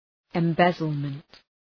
Προφορά
{ım’bezəlmənt}